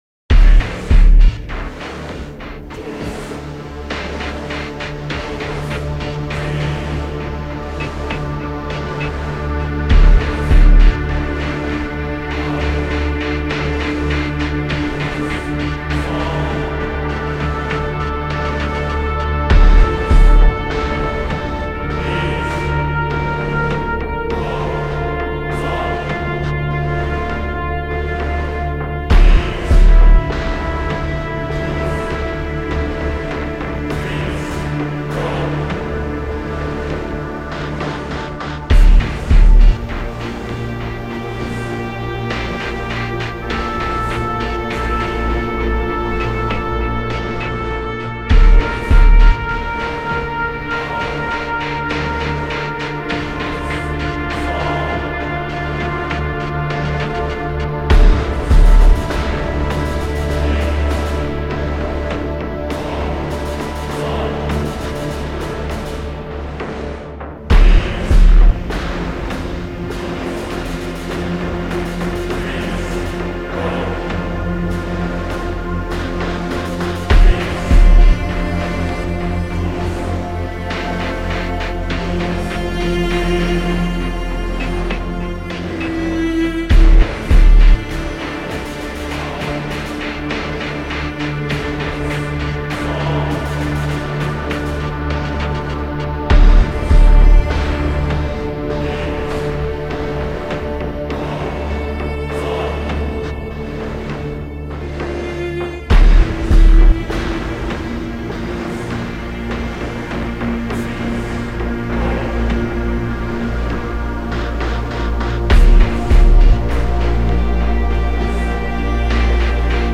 Hybrid track for war strategy an RPG.